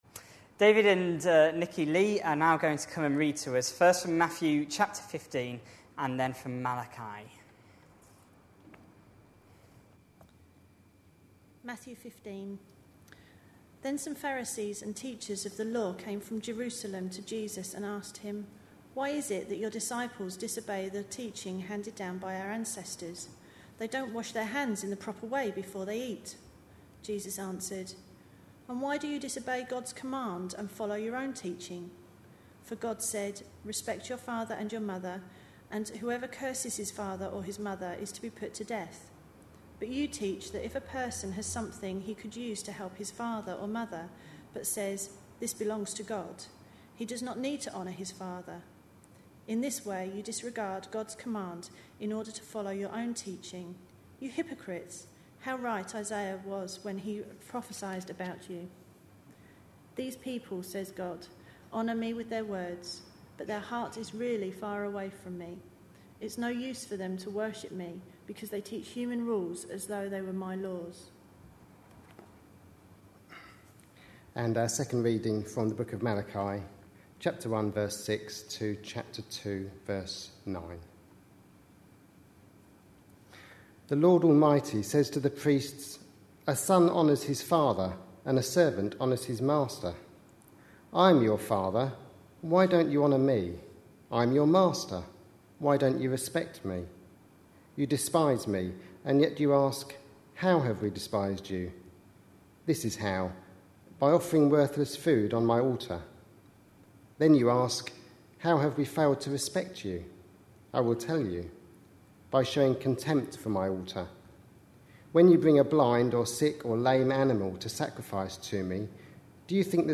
A sermon preached on 9th October, 2011, as part of our Malachi (Sunday evenings). series.